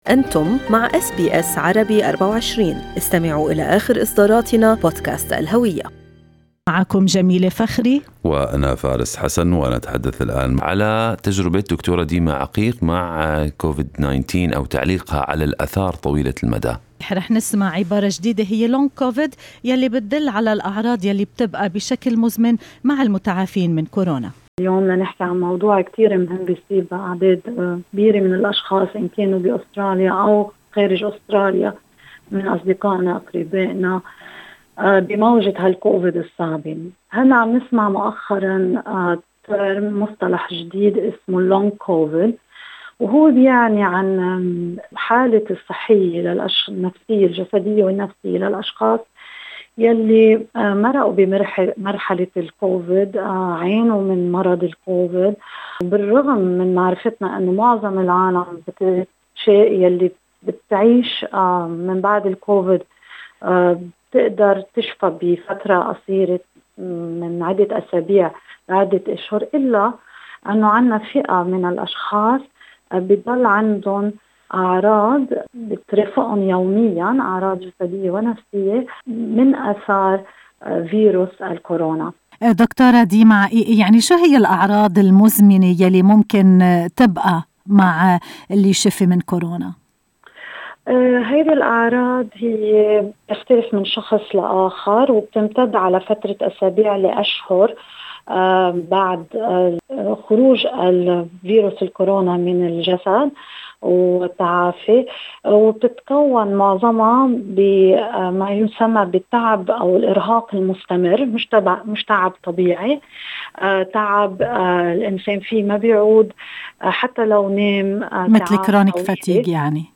وفي حديث مع اس بي عربي 24